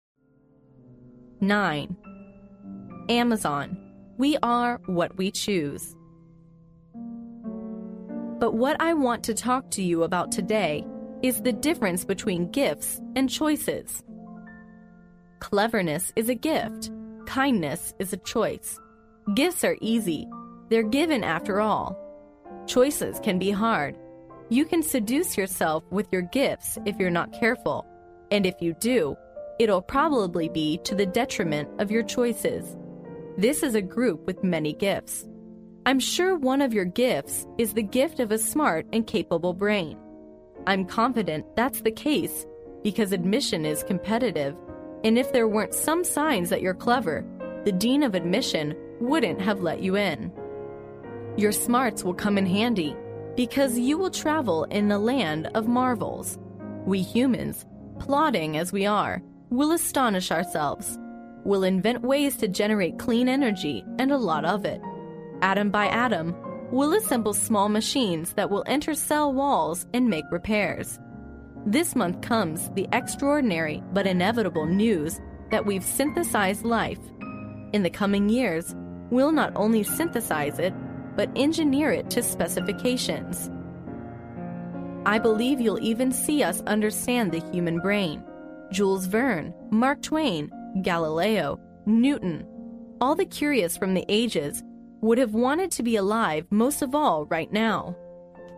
在线英语听力室历史英雄名人演讲 第96期:亚马逊 选择塑造人生(1)的听力文件下载, 《历史英雄名人演讲》栏目收录了国家领袖、政治人物、商界精英和作家记者艺人在重大场合的演讲，展现了伟人、精英的睿智。